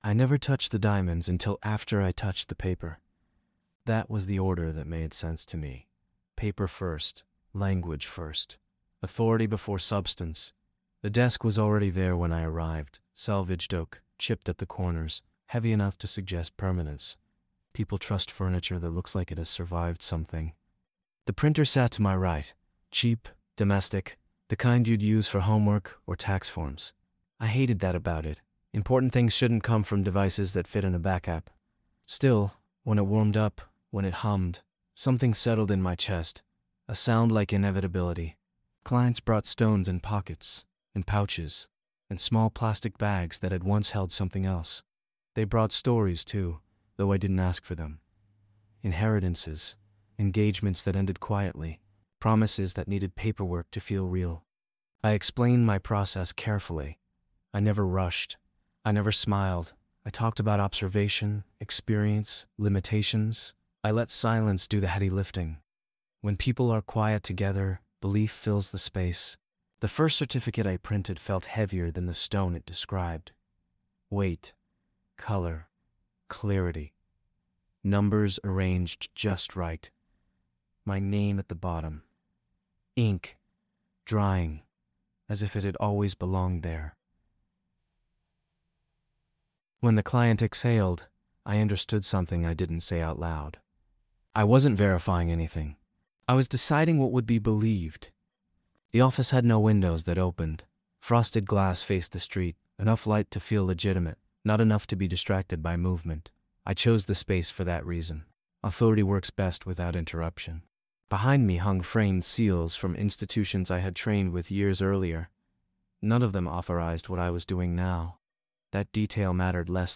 Told in first-person, the episode follows a man who positions himself as a calm, credible gatekeeper in the world of gemstones—issuing documents that look indistinguishable from legitimacy, produced quickly, affordably, and without external verification.